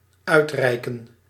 Ääntäminen
US : IPA : [əˈwɔːd]